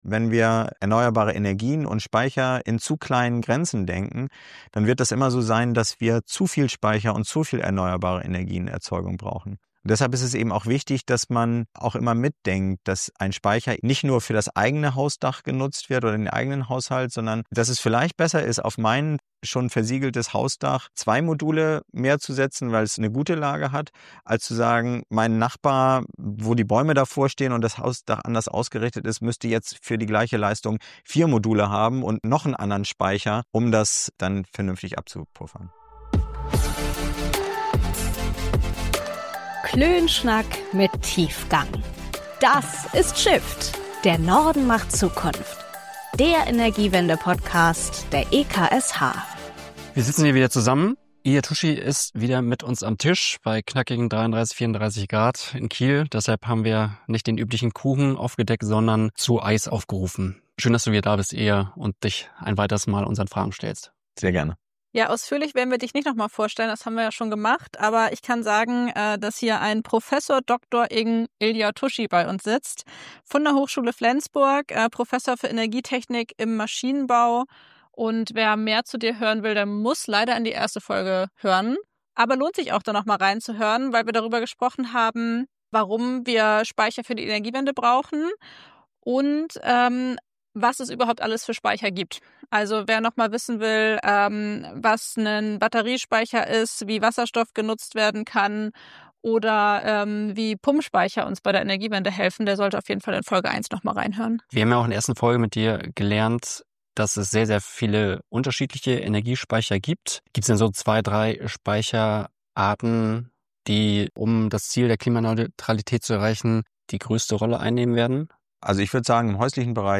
In diesem zweiten Teil unseres Gesprächs, schauen wir genauer auf Heimspeicher wie Solaranlagen, Wärmepumpen und E-Autos.